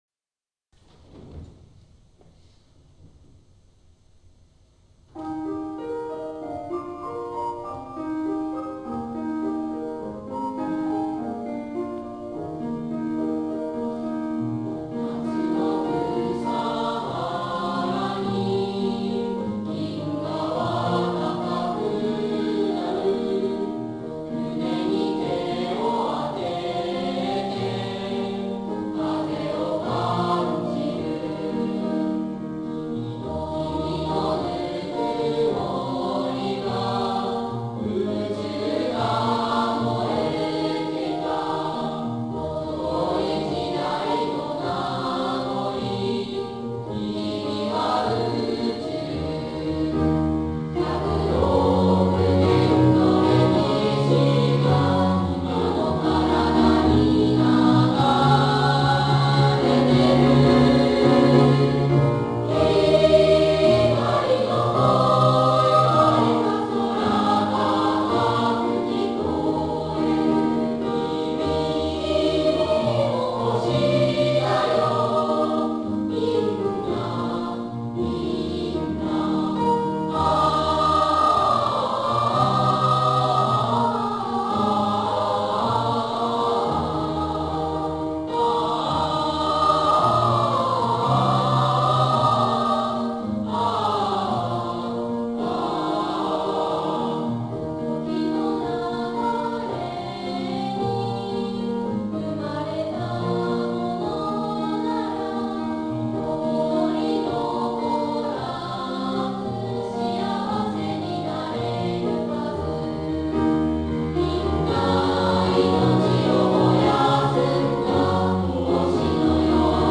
～校内合唱コンクールが行われました（11月12日）～
芸術の秋，この日のために朝夕となく練習を重ねてきた中学生・高校生の歌声が，学内に響きわたりました。